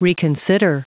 Prononciation du mot reconsider en anglais (fichier audio)
Prononciation du mot : reconsider